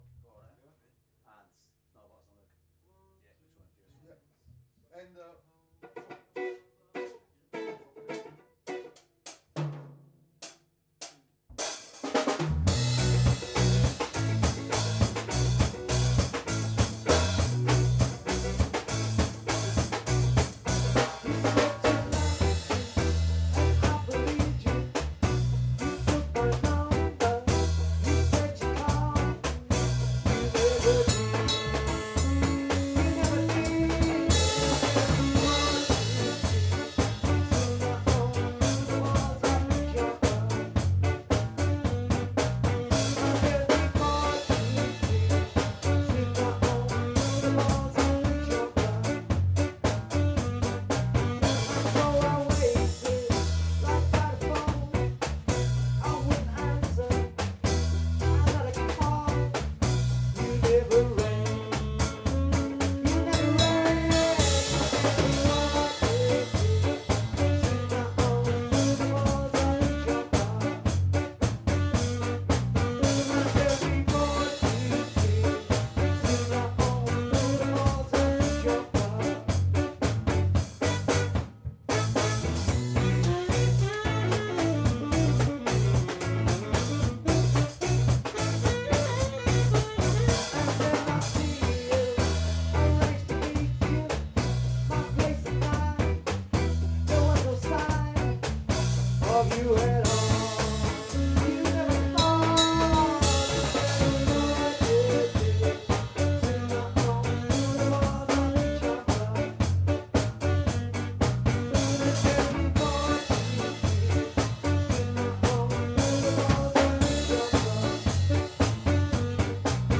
Bored To Tears (jam)
Intro Bm AVer Em Bm x 2 Acho D G A